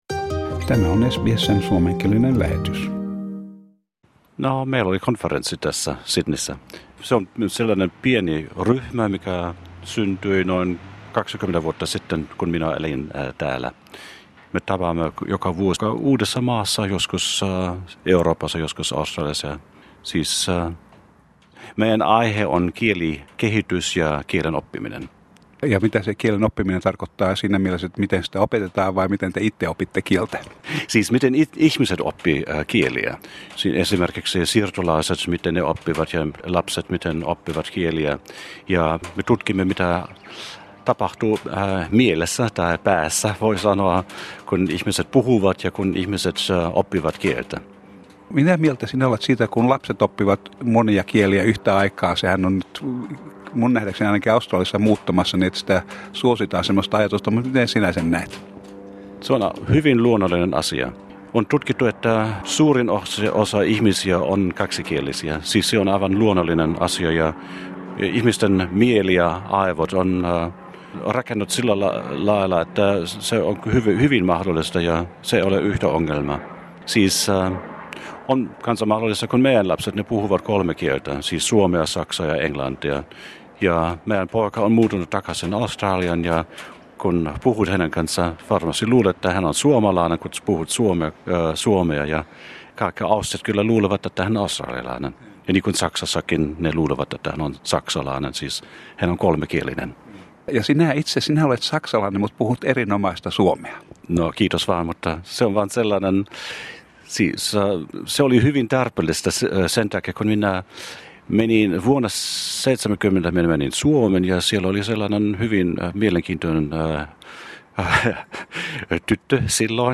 Tämä haastattelu on vuodelta 2010 jolloin minulle tarjoutui tilaisuus kysellä monikielisyyteen liittyviä asioita. Useimmat meista Australiassa asuvista käyttävät ainakin kahta kieltä, joissakin tapauksissa useampia kieliä.